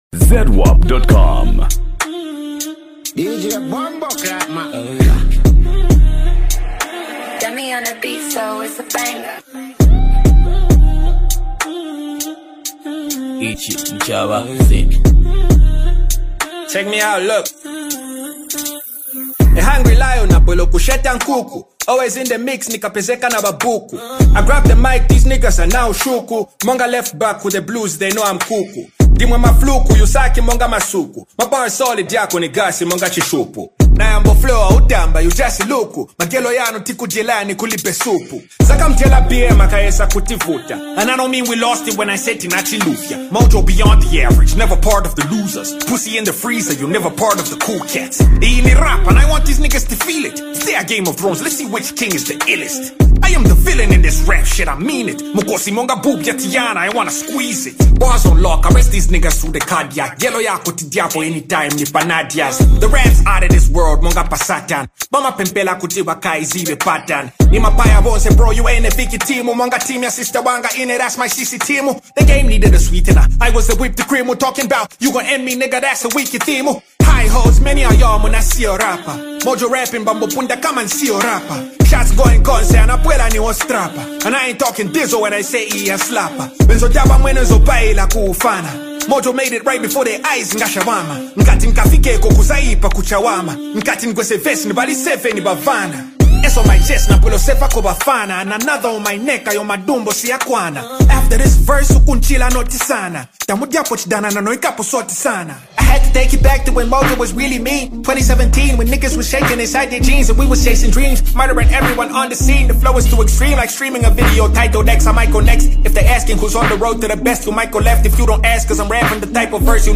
Genre: Afro Pop, Zambia Songs